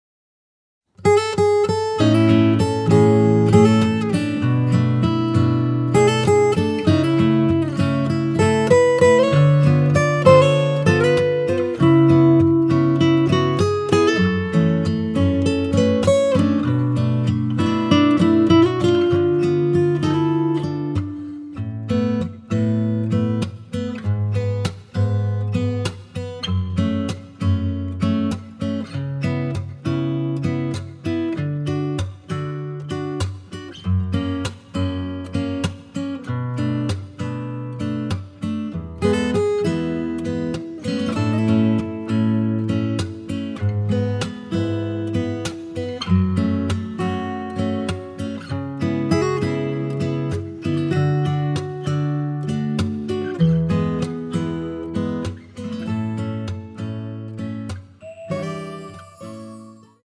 [공식 음원 MR]
앞부분30초, 뒷부분30초씩 편집해서 올려 드리고 있습니다.
중간에 음이 끈어지고 다시 나오는 이유는